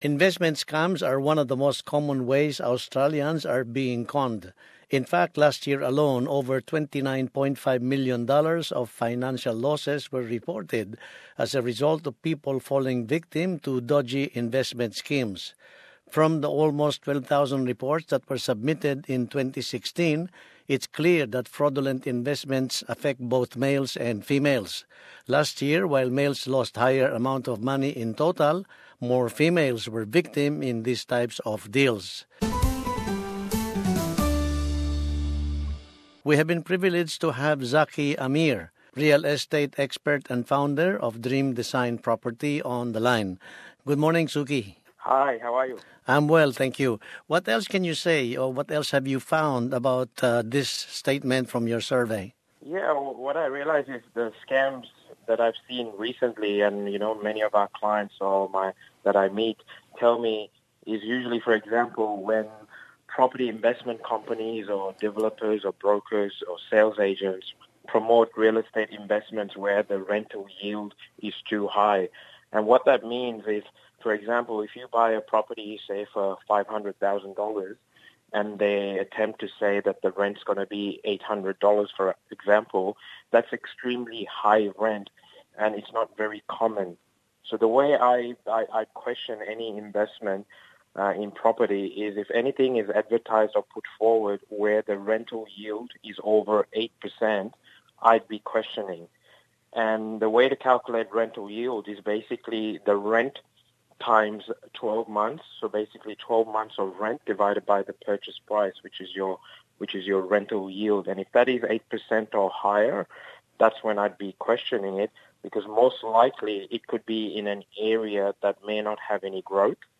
Narito sa ating wika, ang isang ulat tungkol sa mga ginagawang panloloko, kaugnay ng pagbile ng mga tirahan.